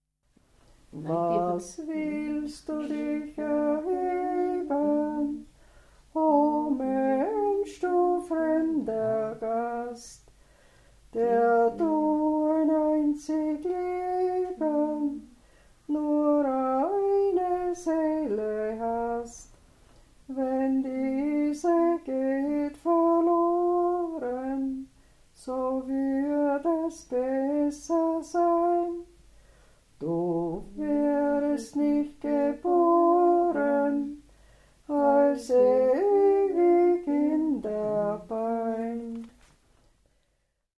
Traditional music in the Styrian / Lower Austrian Wechsel-region; songs during the corpse-watch in the farmhouse of the deceased, CDs, historical recordings, dictionary of local dialect; incipits
Church music
Folk & traditional music